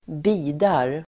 Uttal: [²bi:dar]